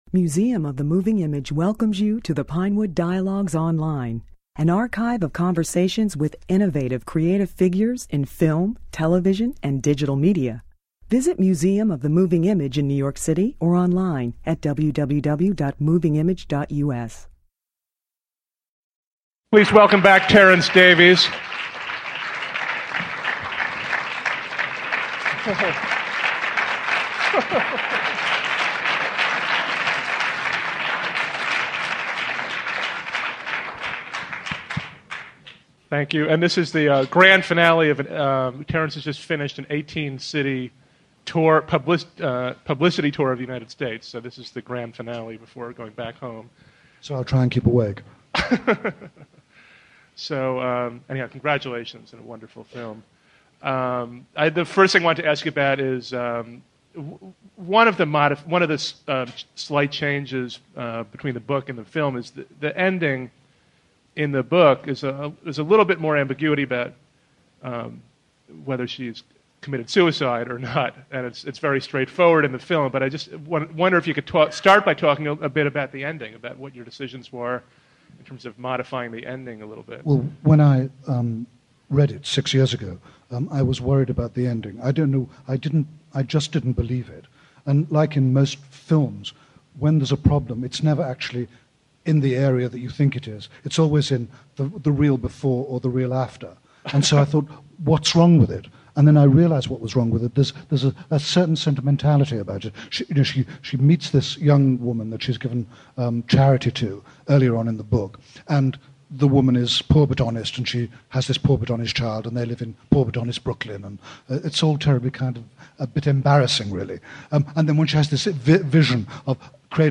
This discussion took place just before the movie's U.S. release. Because of the quiet intensity of his films, the biggest surprise here may be the mischievous humor that Davies displays throughout the talk.